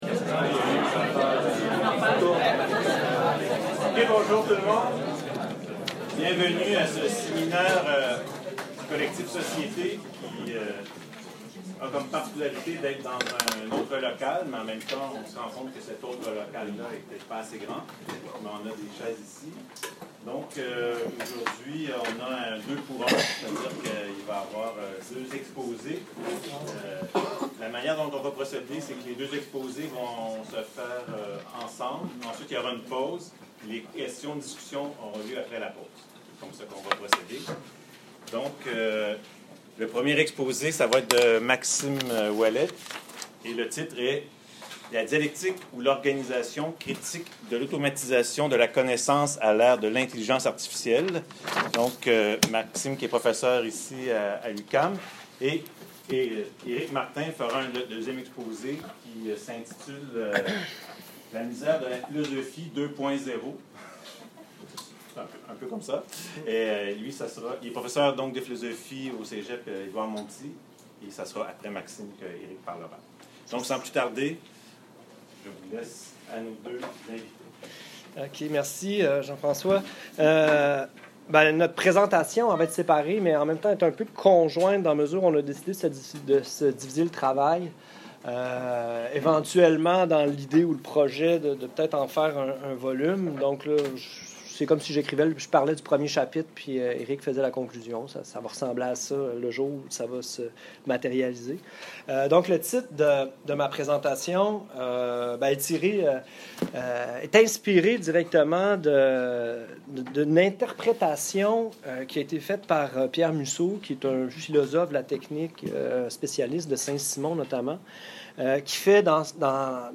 Deux conférences seront données à cette occasion :